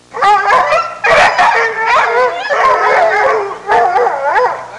Hounds With Scent Sound Effect
hounds-with-scent-1.mp3